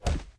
Punch.ogg